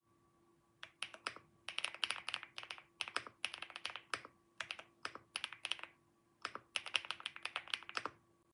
1. コトコト系キーボードの擬音を英語でthockというみたいです。 ↩
rainy75.mp3